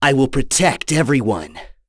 Kasel-Vox_Skill5.wav